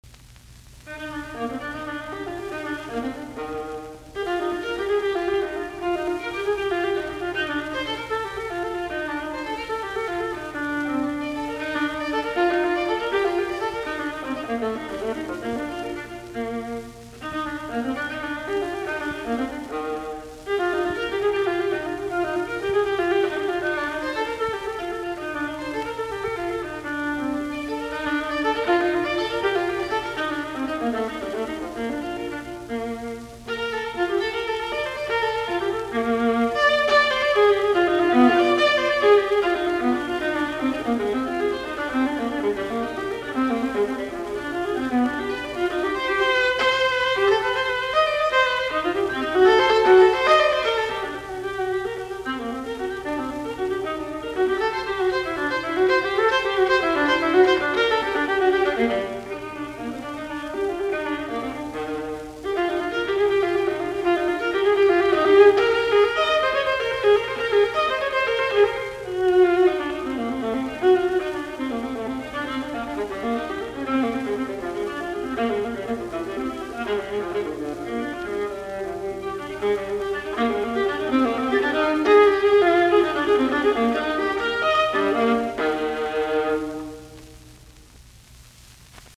alttoviulu